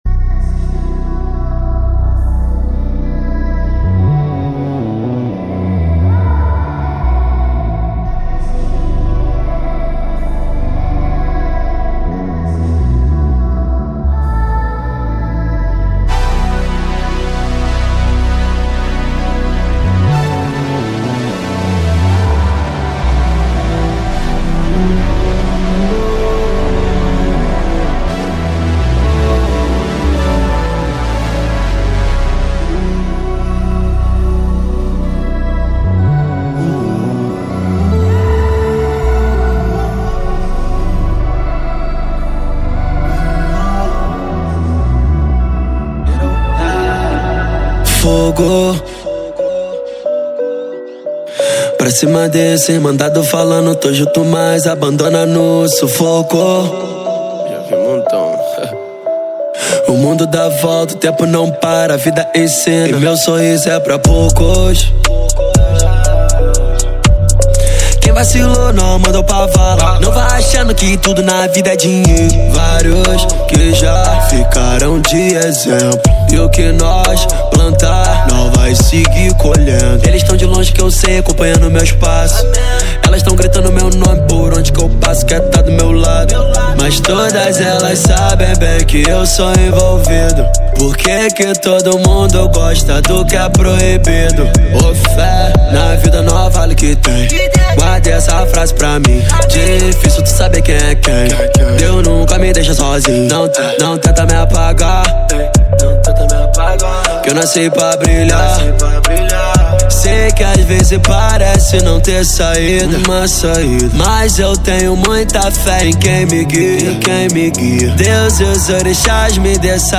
2024-06-05 13:43:04 Gênero: Funk Views